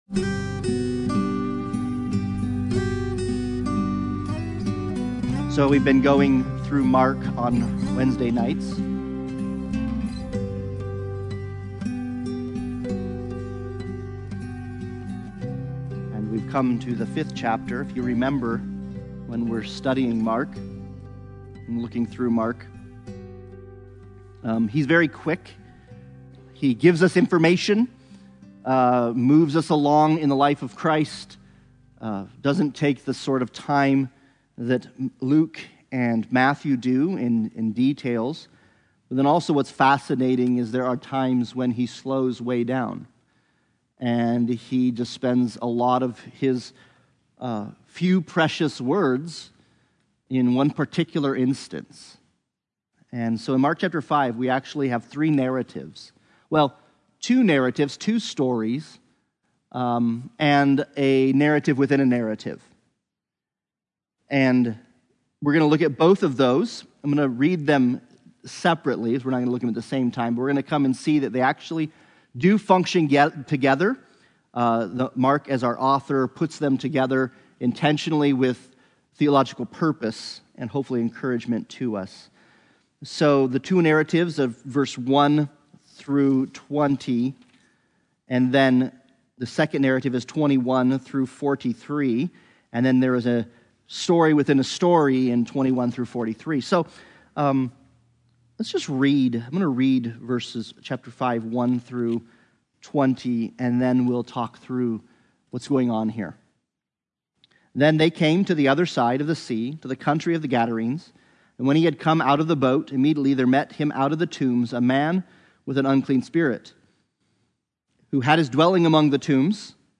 Sunday Bible Study « Walk Wisely